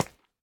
Minecraft Version Minecraft Version 25w18a Latest Release | Latest Snapshot 25w18a / assets / minecraft / sounds / block / candle / step4.ogg Compare With Compare With Latest Release | Latest Snapshot
step4.ogg